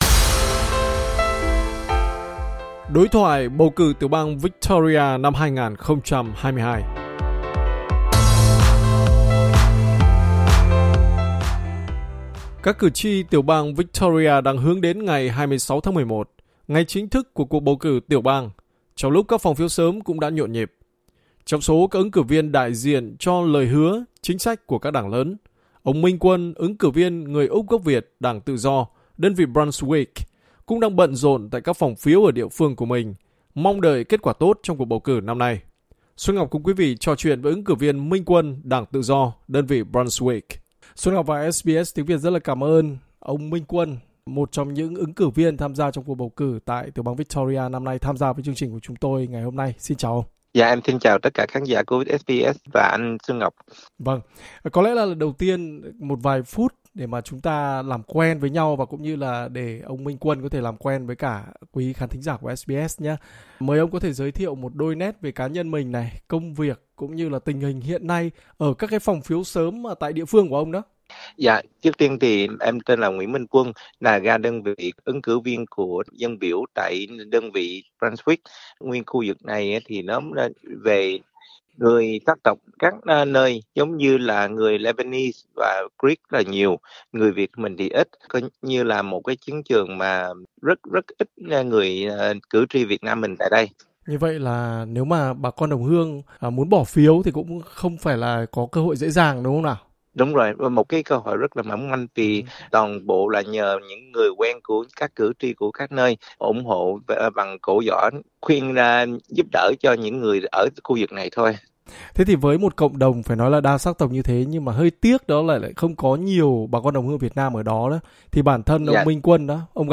SBS cùng quý vị trò chuyện với ứng cử viên Úc gốc Việt này.